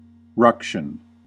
Ääntäminen
Synonyymit row uproar brawl fracas disturbance ruckus Ääntäminen UK : IPA : /ˈɹʌk.ʃən/ US : IPA : /ˈɹʌk.ʃən/ Haettu sana löytyi näillä lähdekielillä: englanti Määritelmät Substantiivit A noisy quarrel or fight .